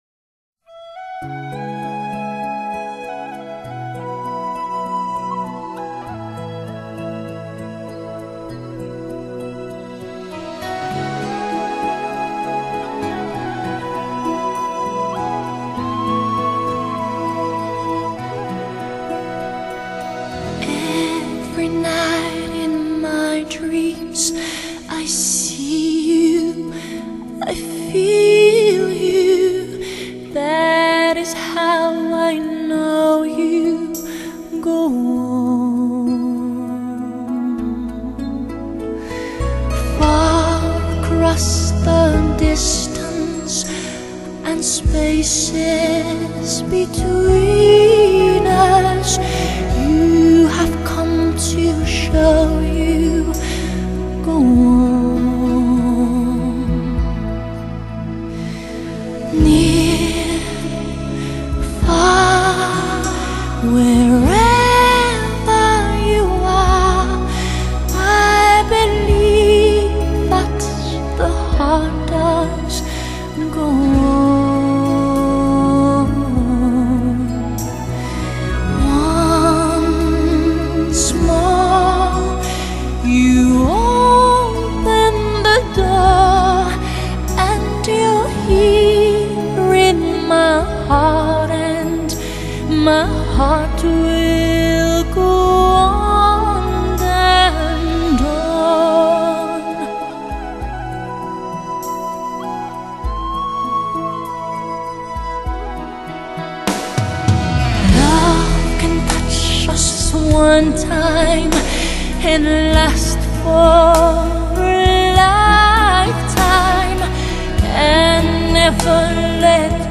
Genre: Pop, Soul, R&B, Ballad